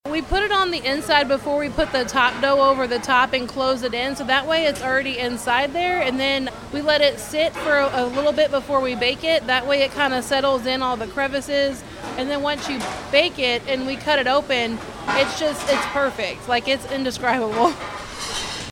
Because a reporter asks, I really did talk to the chef about how they managed to get gravy in there so it wouldn’t dribble all over you as you ate or make the crust soggy.